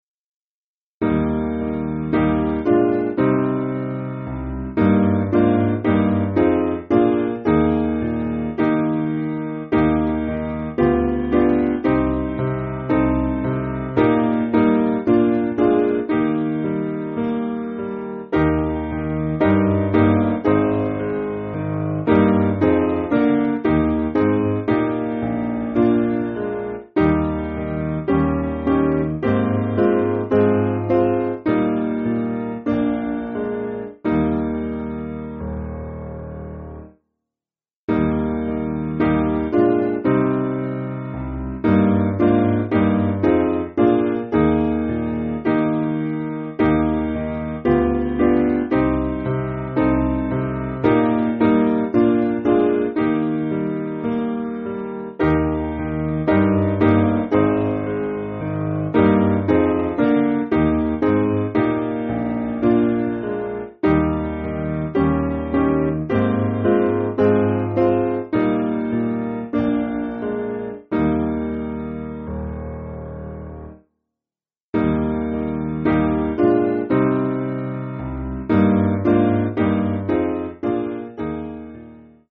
Simple Piano
(CM)   4/Eb